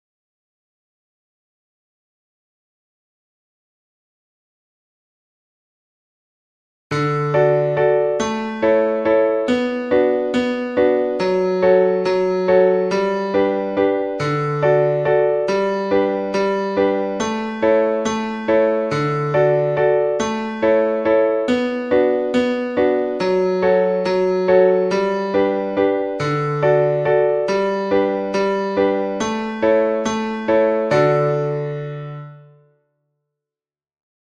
The first one starts with duple subdivision and finishes with triple and the second one starts with a triple subdivision and finishes with a duple one.
Triple_duple
ternario-binario.mp3